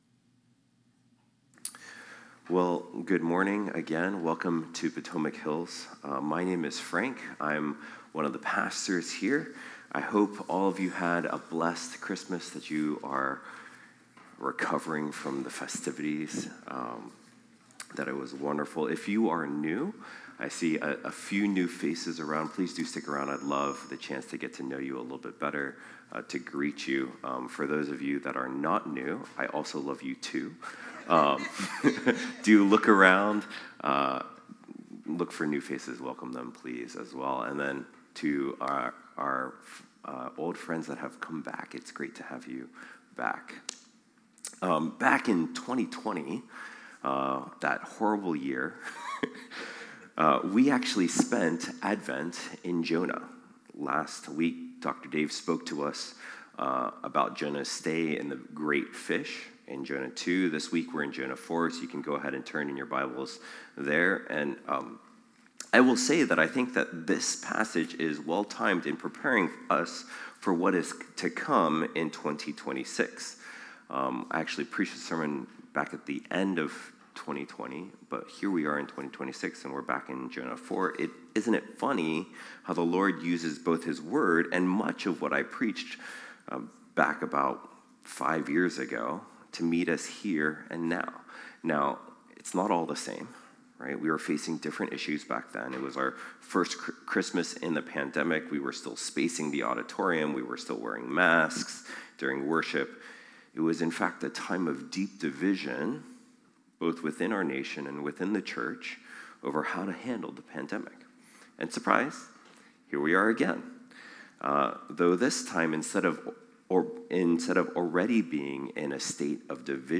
Potomac Hills Presbyterian Church Sermons